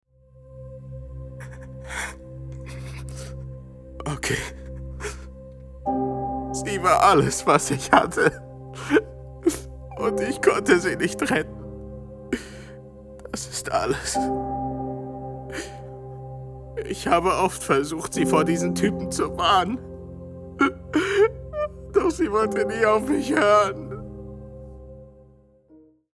German Voice Over Artist
Sprechprobe: Sonstiges (Muttersprache):